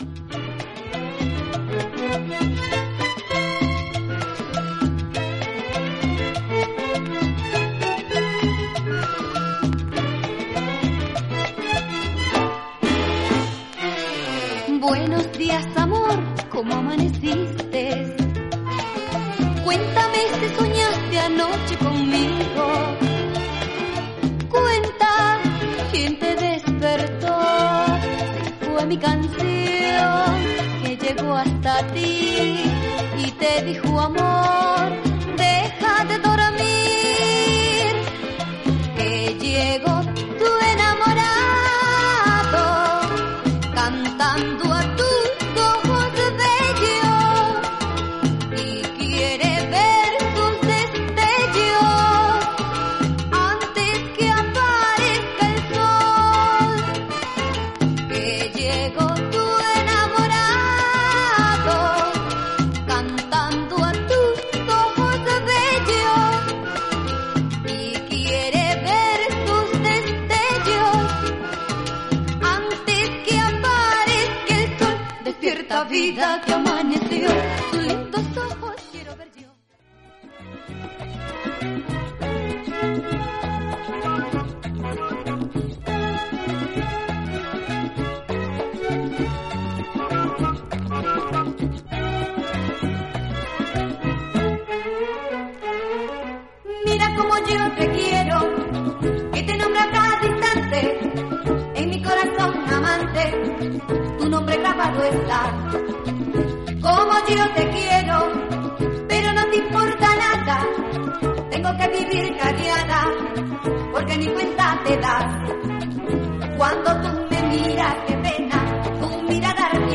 ベネズエラの女性シンガー
クンビア風のリズムと哀愁のあるメロディが特徴的な
爽やかで清涼感のあるラテン・ナンバー